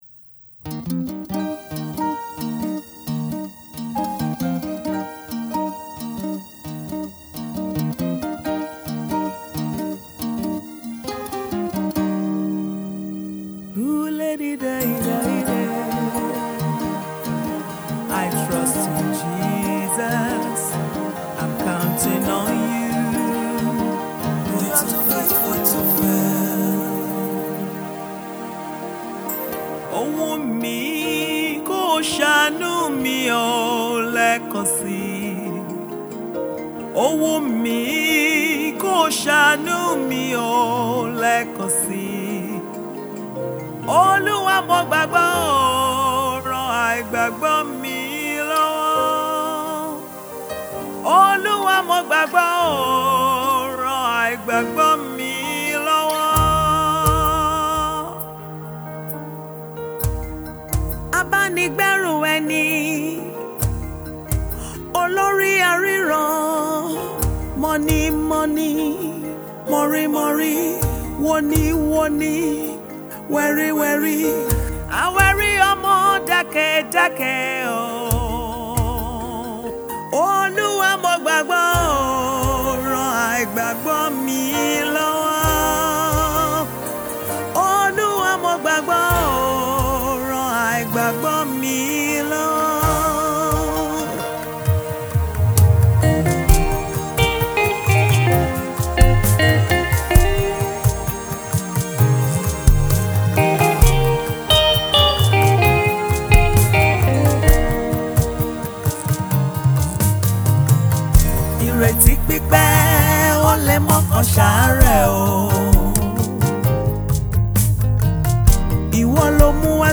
Gospel Artiste
single